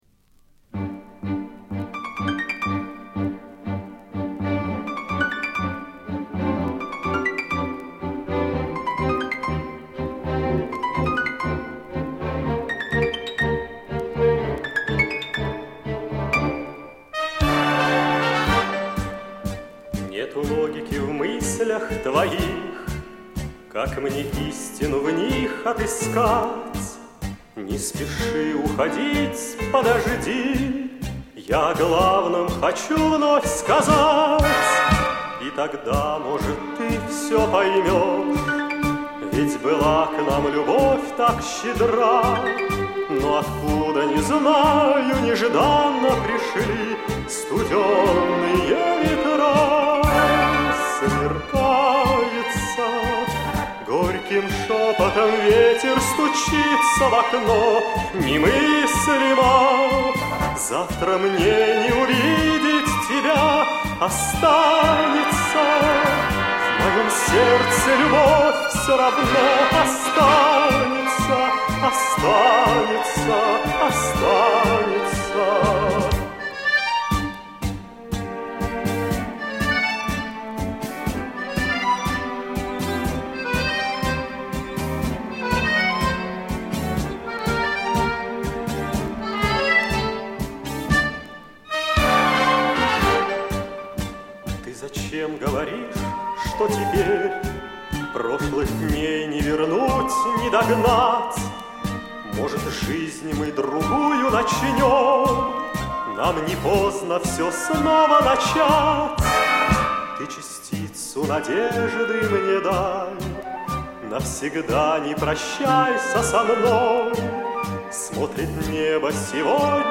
Какая красота-танго это!